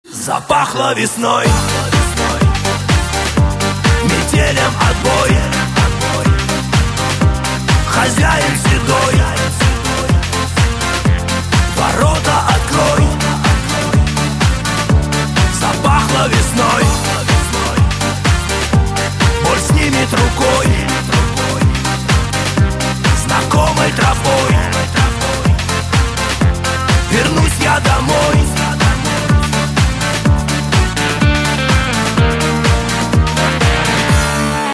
Категория : Шансон (реалтоны)